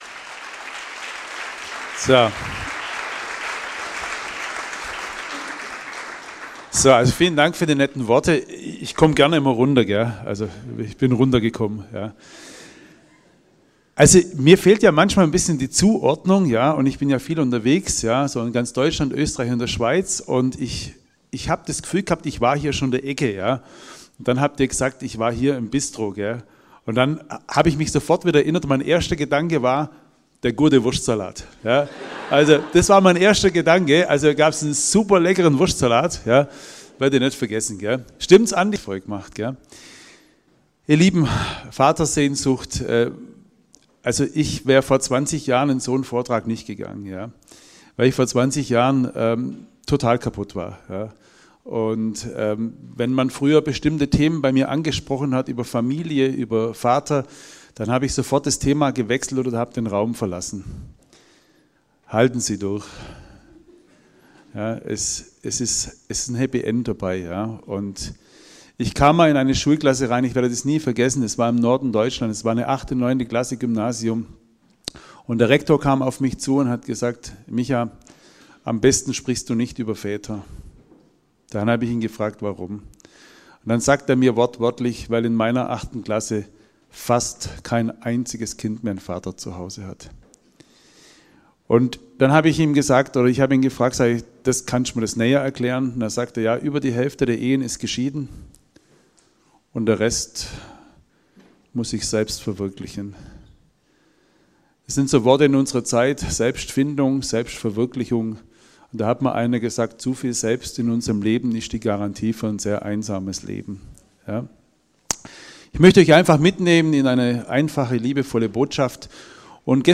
Predigten für die ganze Familie